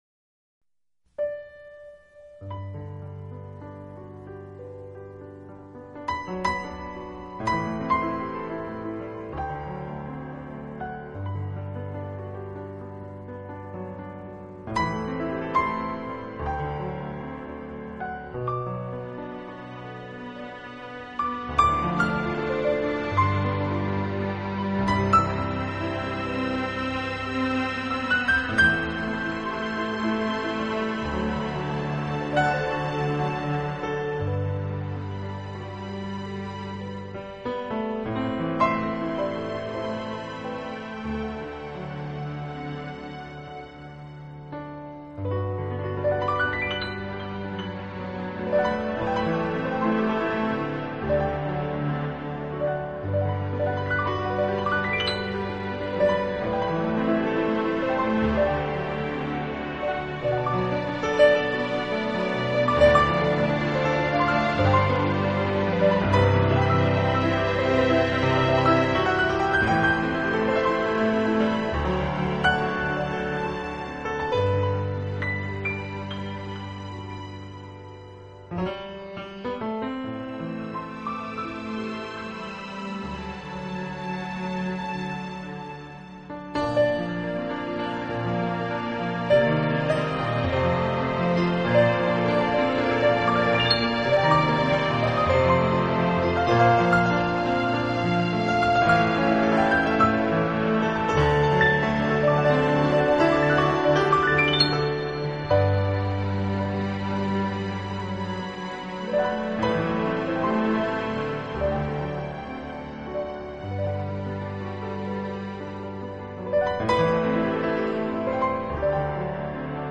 【新世纪钢琴】
音乐类型：Easy Listening